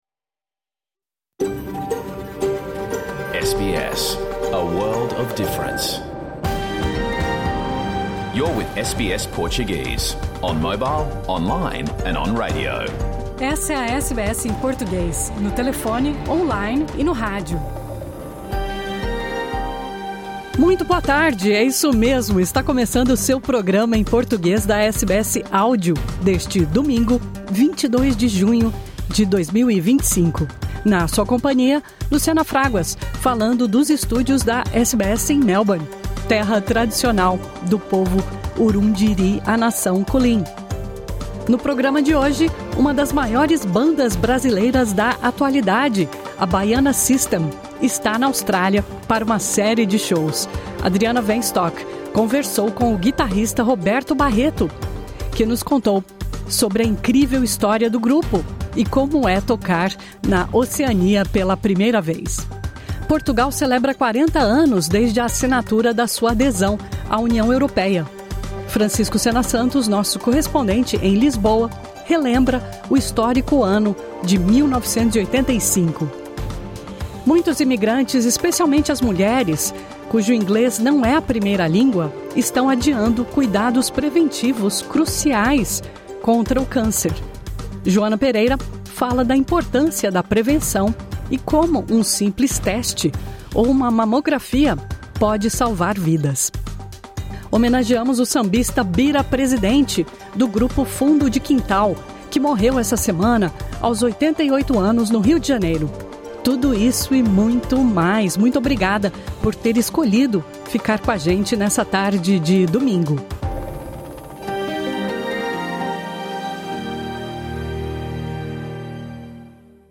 Programa ao vivo | Domingo 22 de junho
Conversamos com uma das maiores bandas brasileiras da atualidade, a BaianaSystem. em turnê pela Austrália. Portugal celebra 40 anos desde a assinatura da sua adesão à União Europeia. Muitos imigrantes, especialmente as mulheres, estão adiando exames preventivos cruciais contra o câncer.